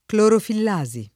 [ klorofill #@ i ]